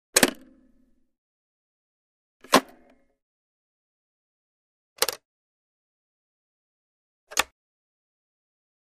Звуки детских игрушек
Детская игрушка черт из табакерки вылетает из коробочки